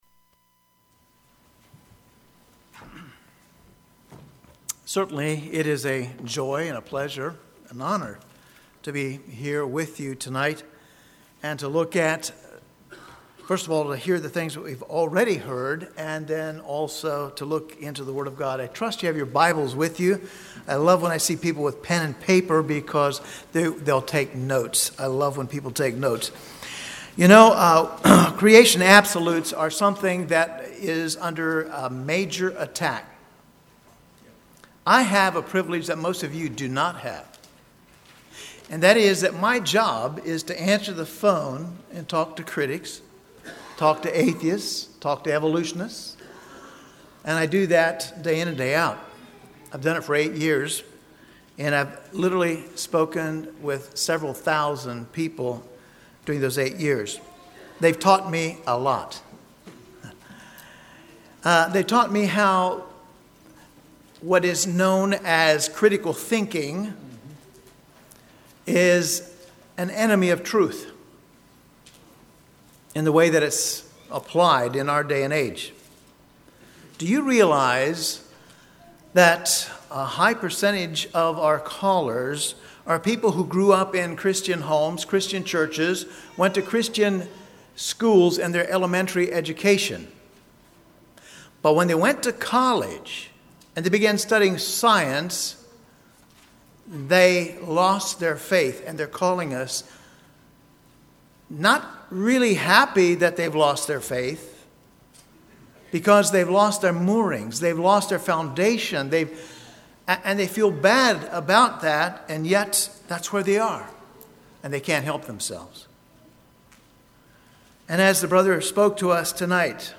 Absolutes Established Congregation: Swatara Speaker